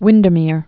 (wĭndər-mîr)